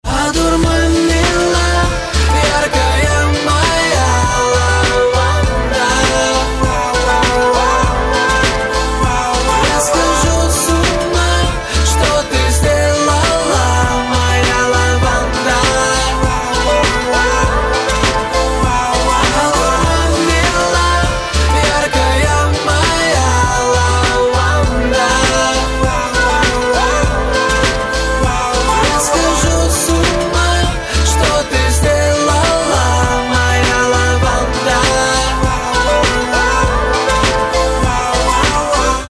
• Качество: 320, Stereo
поп
мужской вокал
лирика
чувственные